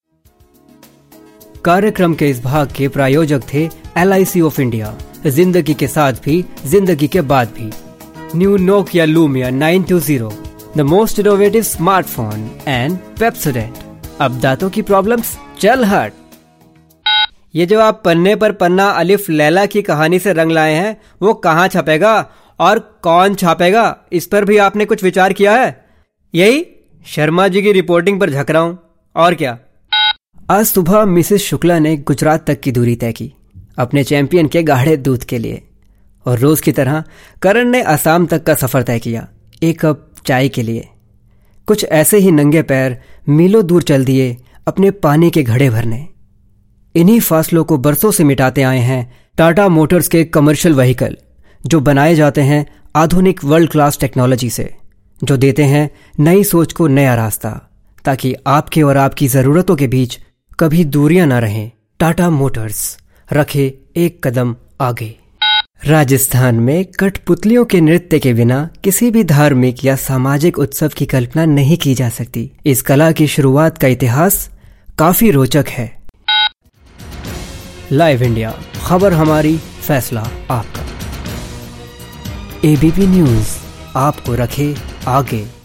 a professional voice over artist with good command over hindi , english and punjabi
Sprechprobe: eLearning (Muttersprache):